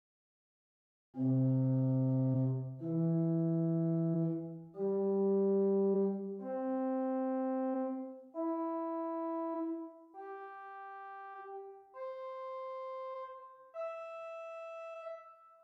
These are the names most commonly given to the Principal of 2' pitch in the manuals and 4' pitch in the pedal. The pipes are of open metal construction.
Fifteenth 4', Pedal St. Anne's Church, Moseley, Birmingham, England Nicholson, 1969
arpeggio
Ped_Fifteenth4_arp.mp3